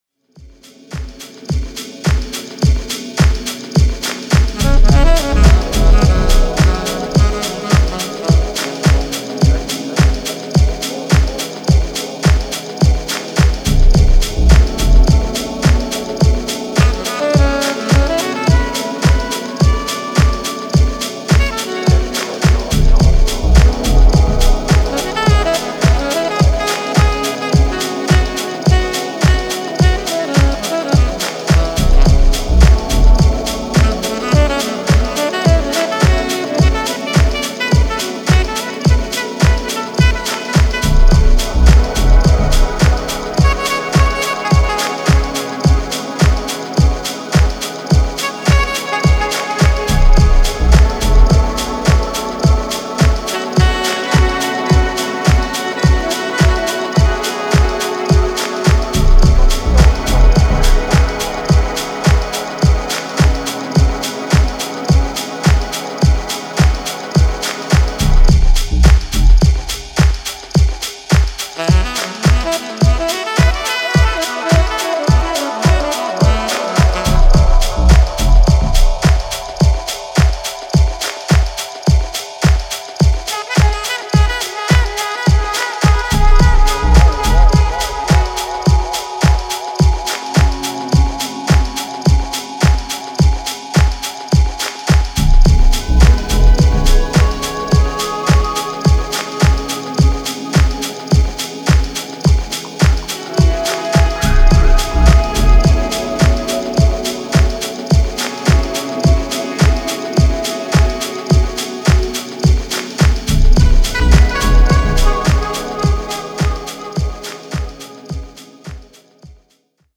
スピリチュアルなディープハウス